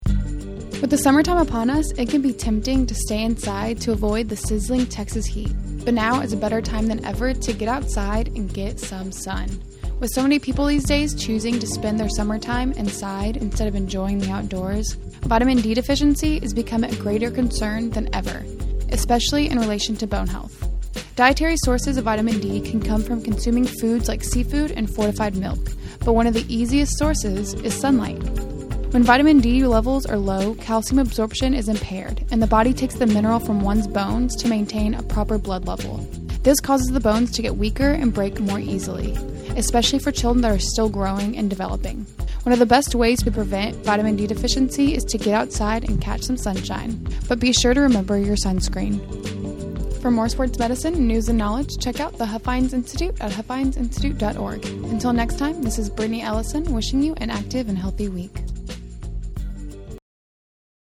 Be sure to check out future weekly Huffines Institute Human Performance Minute broadcasts at TexAgs SportsRadio (The Zone 1150 AM) every Wednesday morning at 10:45 am.